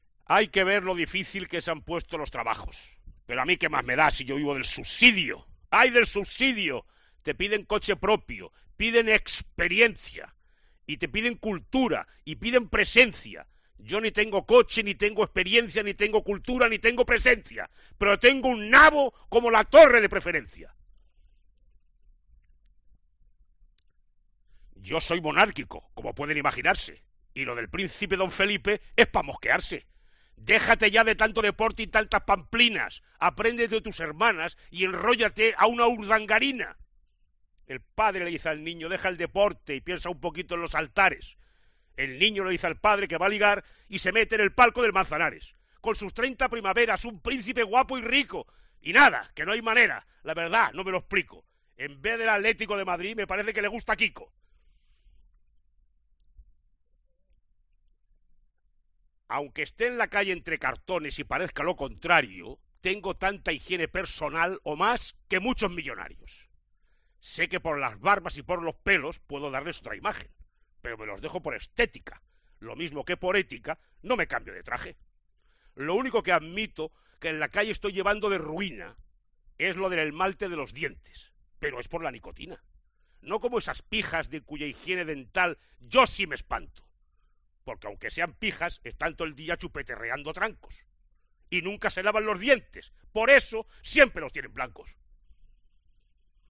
Los mejores cuplés de Juan Carlos Aragón en la voz de Tomás Guasch
El conocido periodista se atreve a recitar algunos de los cuplés más gamberros del autor gaditano
Su acento y su gracejo delatan el enorme talento de Guasch que le da su peculiar forma de interpretar a varios cuplés antológicos de Juan Carlos Aragón.